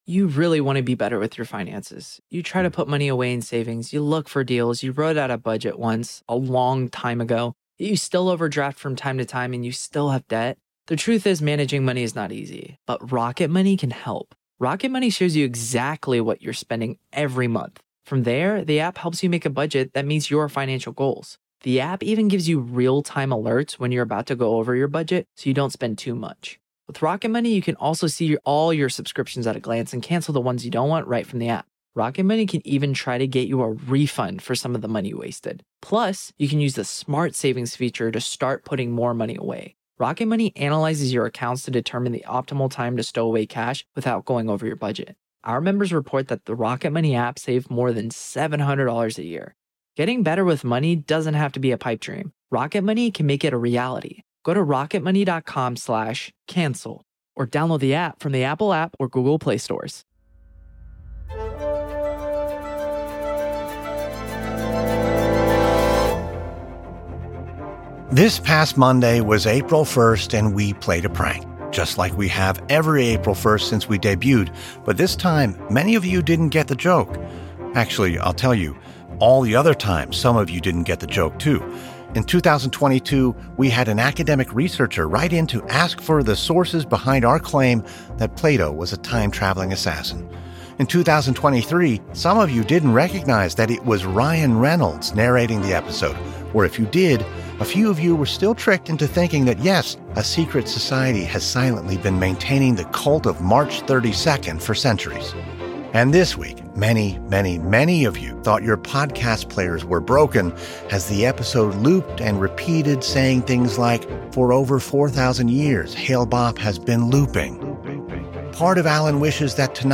On today’s Saturday Matinee, the series premiere of QEZ: the comedy quiz show where you win by being smart, or pretending that you are.